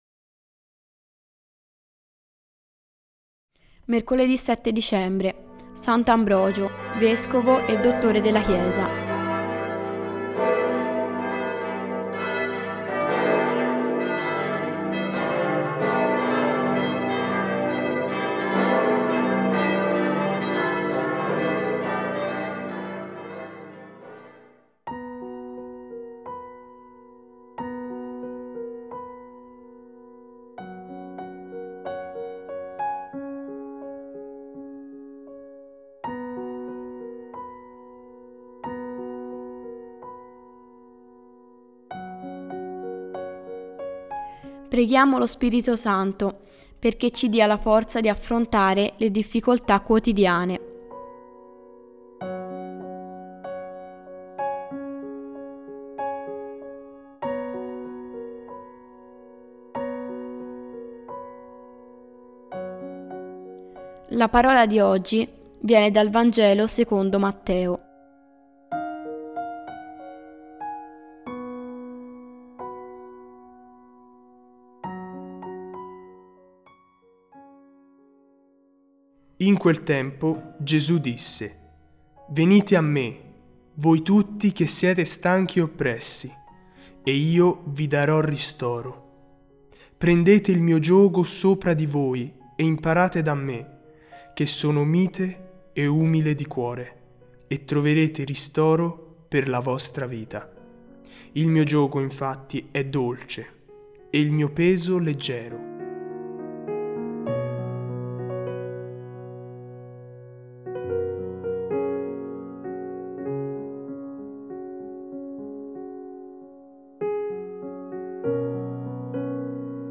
Piano Worship Music